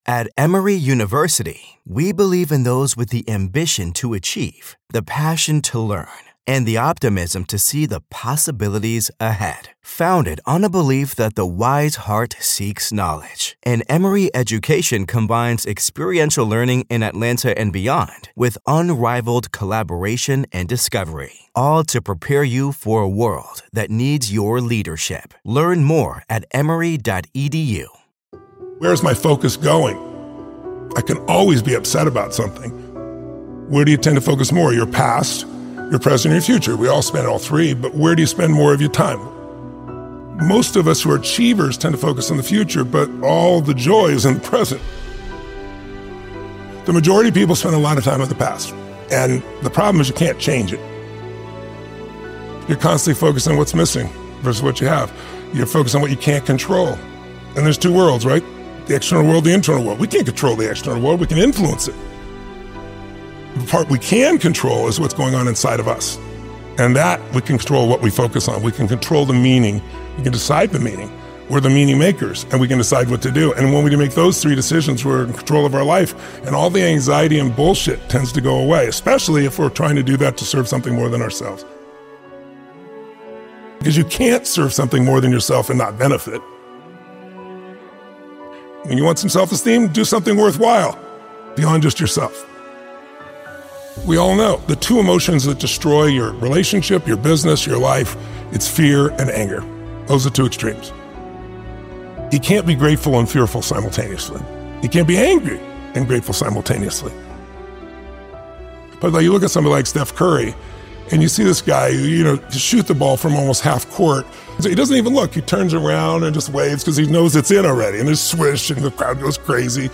Tony Robbins - Focus on yourself and move in silence motivational speech
In this electrifying episode, Tony Robbins delivers a powerful message about the transformative power of self-focus and quiet determination.